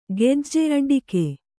♪ gejje aḍḍike